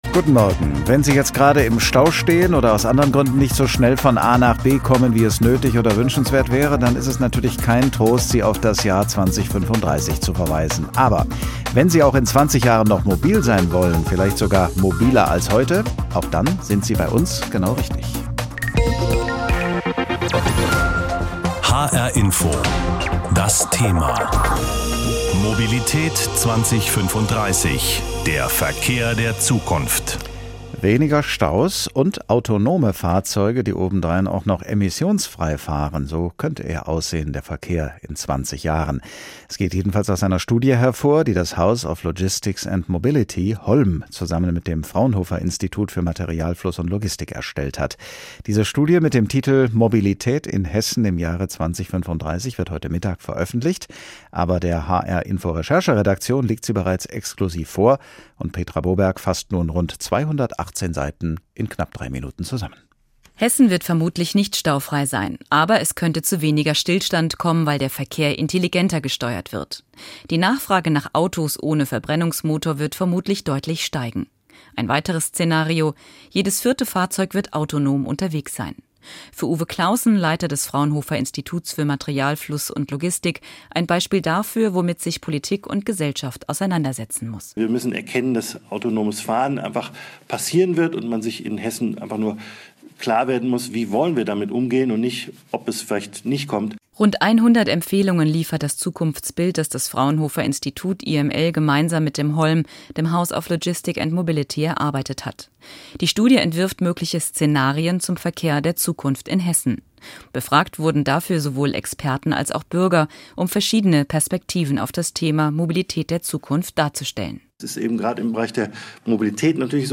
Beitrag